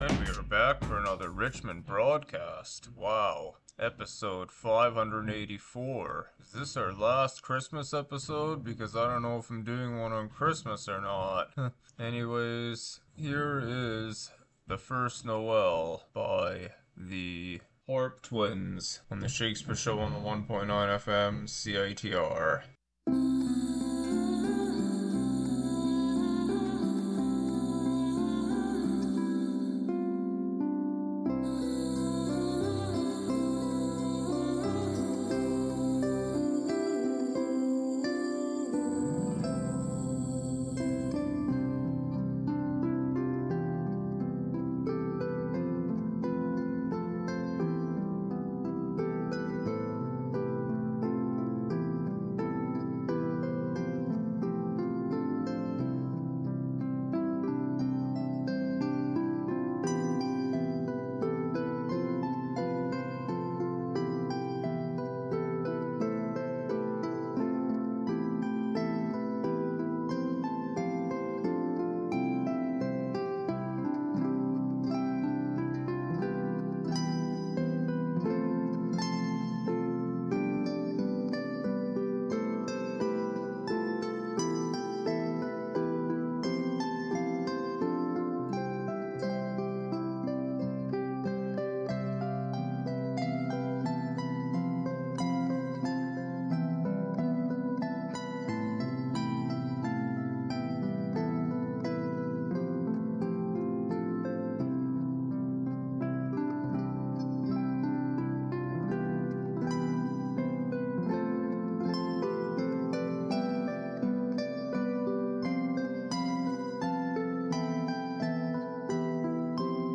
an eclectic mix of music, Some Christmas